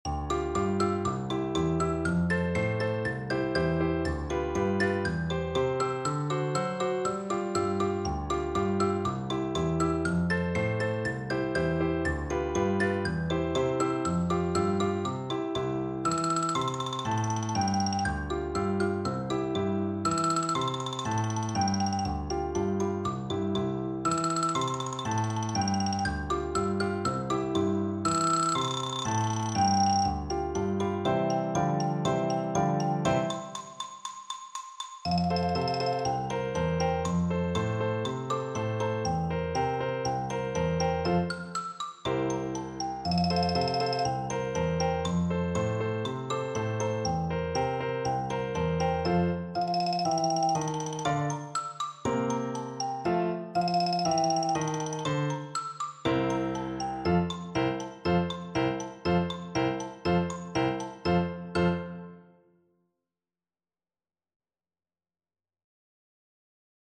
Xylophone
4/4 (View more 4/4 Music)
C major (Sounding Pitch) (View more C major Music for Percussion )
Allegro = 120 (View more music marked Allegro)
Percussion  (View more Intermediate Percussion Music)
Classical (View more Classical Percussion Music)
offenbach_can_can_XYL.mp3